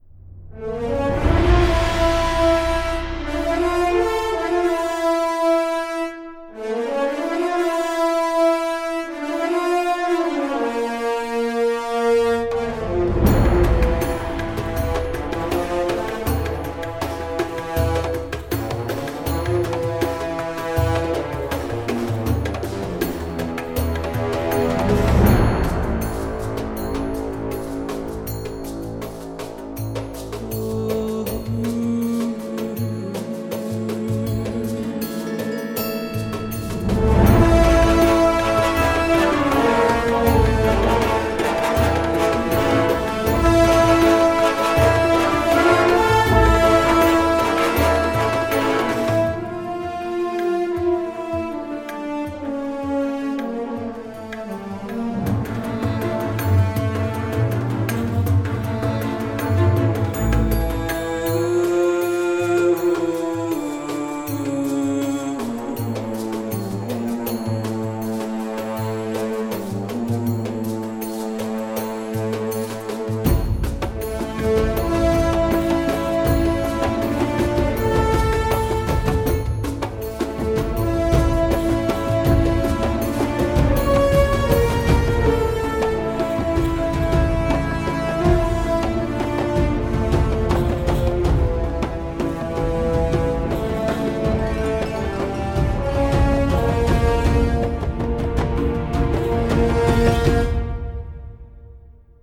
3. 管弦乐
它由 12 个法国号组成，是一个强大、明亮的高音铜管音色库。
它还具有真正的连奏功能，可以演奏流畅的旋律线。
它使用了多个麦克风位置，包括近场、中场、远场和环境，让你可以根据需要调整混响和空间感。